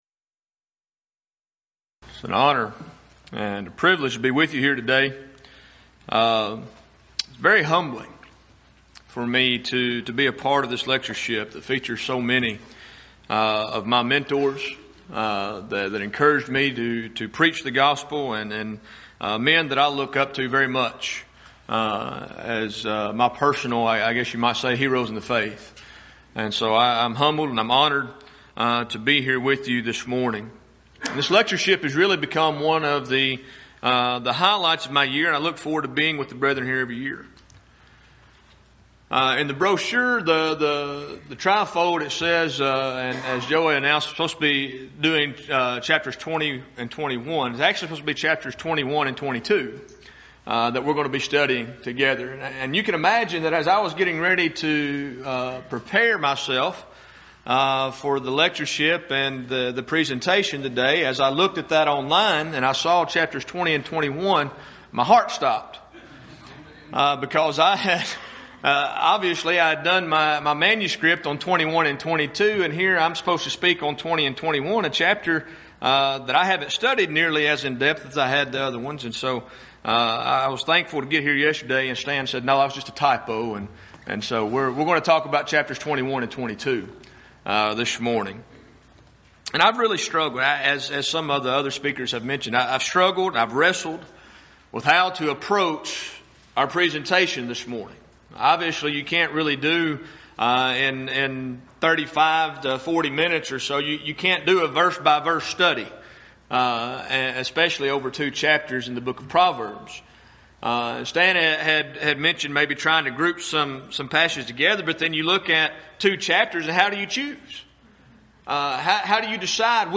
Event: 13th Annual Schertz Lectures Theme/Title: Studies in Proverbs, Ecclesiastes, & Song of Solomon
lecture